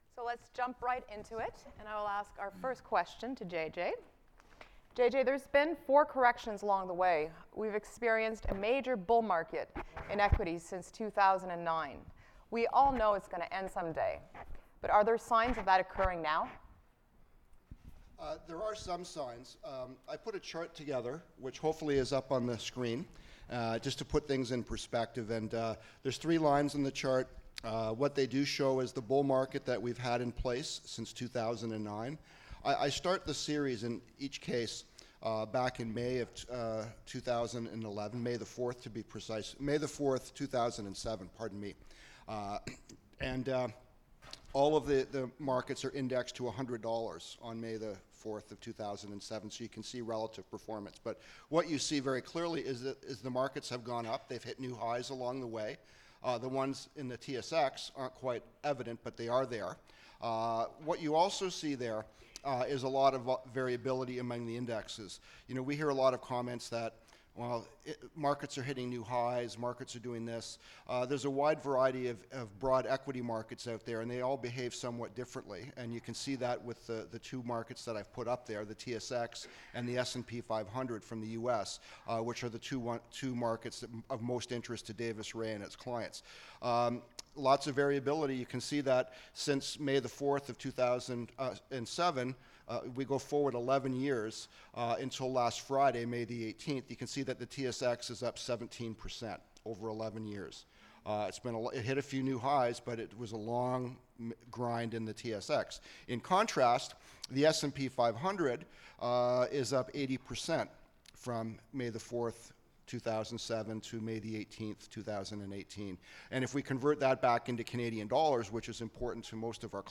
Click to listen to what out investment team had to say!
dr-investor-day-panel-2018.mp3